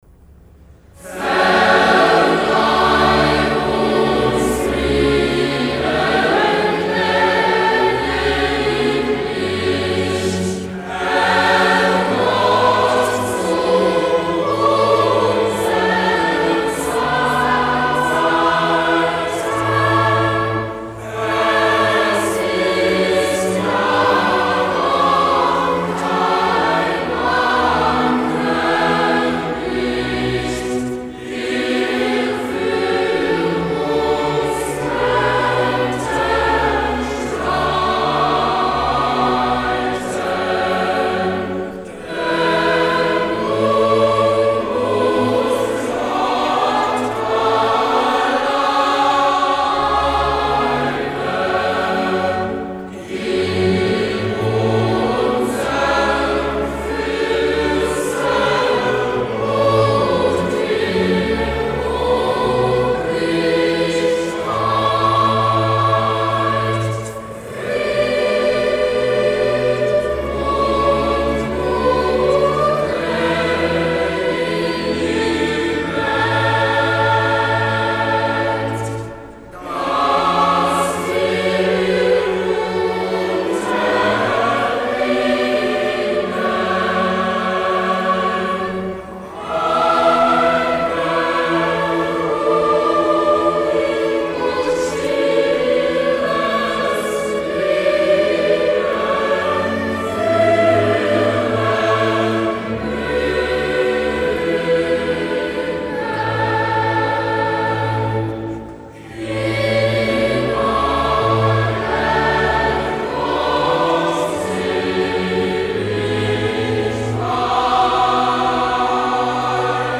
These are performances historically and musically unique, sung with power and passion.
THOMANERCHOR & Gewandhaus Orchestra – Günther RAMIN – Vol.2